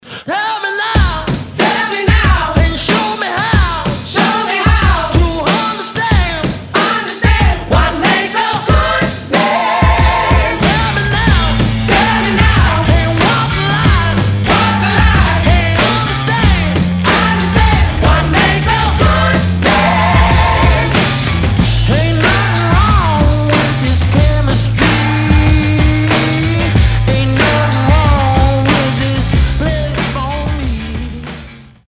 • Качество: 320, Stereo
мужской вокал
громкие
indie rock
Neo Soul
Funk Rock